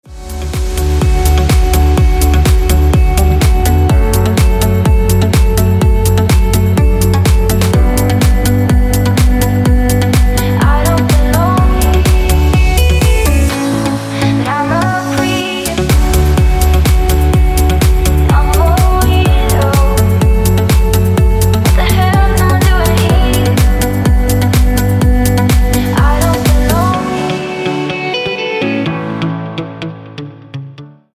• Качество: 256, Stereo
женский вокал
deep house
dance
Electronic
club
Chill
vocal